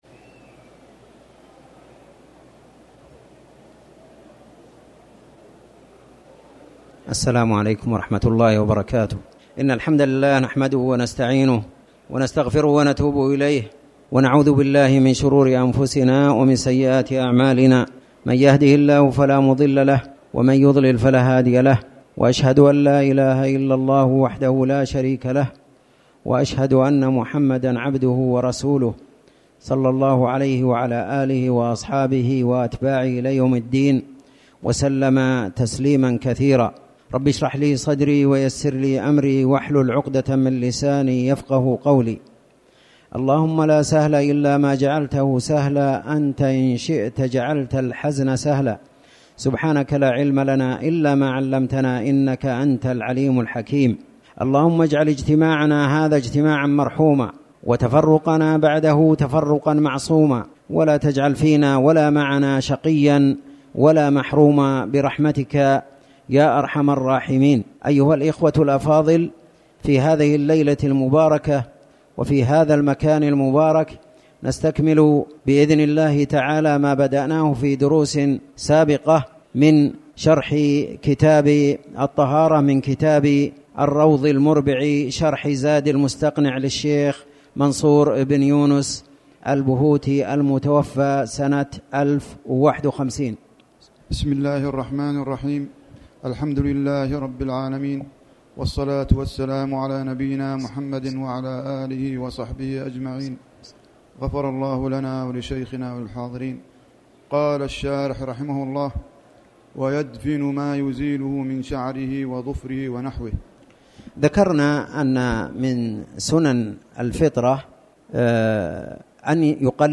تاريخ النشر ٢ رجب ١٤٣٩ هـ المكان: المسجد الحرام الشيخ